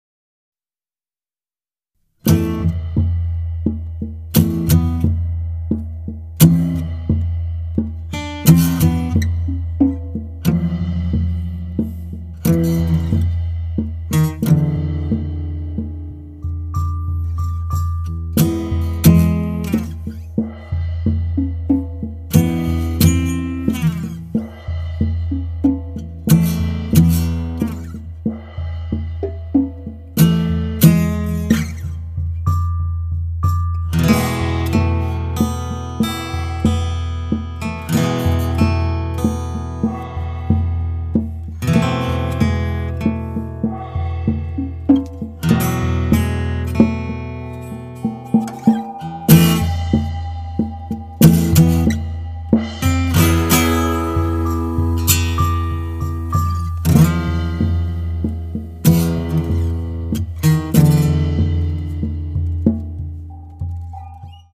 パーカッションとギターによる最小単位のオーケストラが奏でるのは
guitar